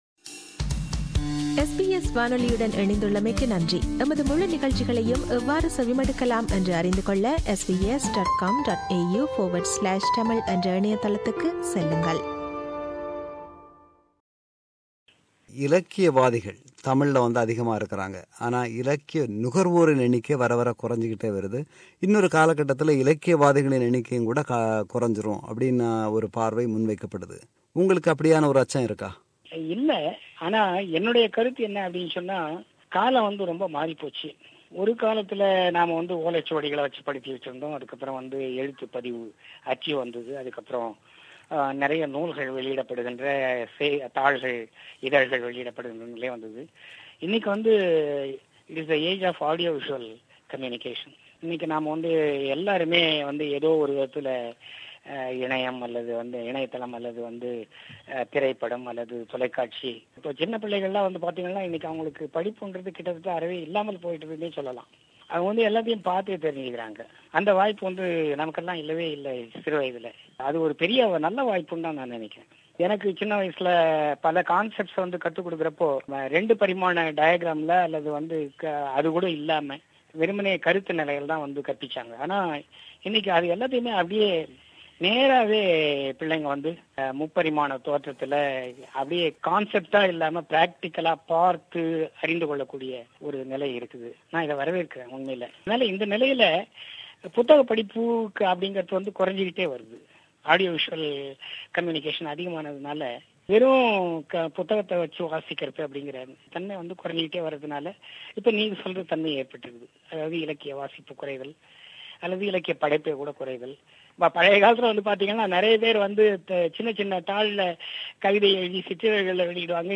Interview Part 2